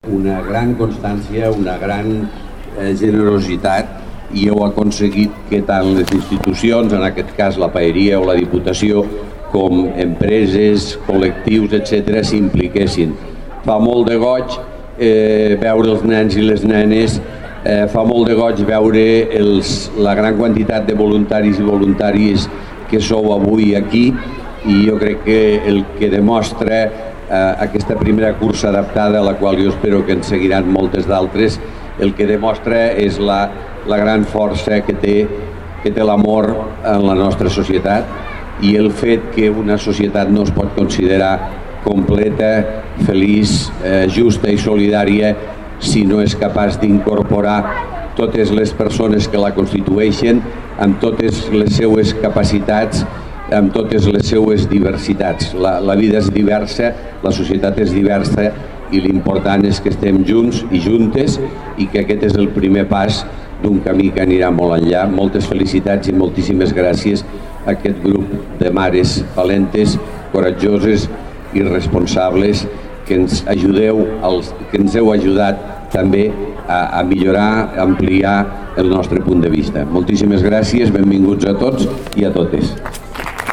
tall-de-veu-miquel-pueyo